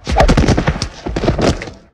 bodyslam.ogg